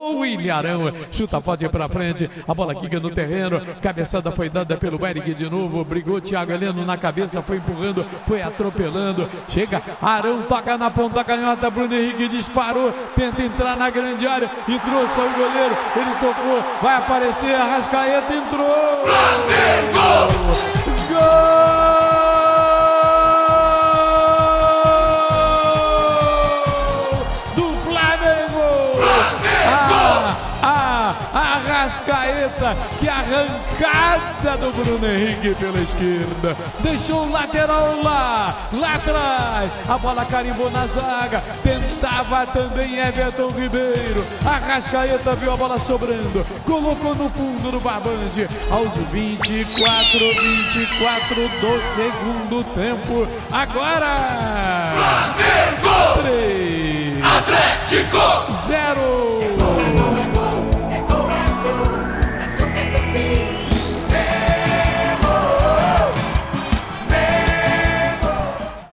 Ouça os gols da vitória do Flamengo sobre o Athletico com a narração de José Carlos Araújo